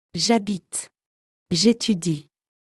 *Attention : quand « je » est suivi d'une voyelle ou d'un « h » muet, on fait l'élision : j'habite (je habite), j'étudie (je étudie)...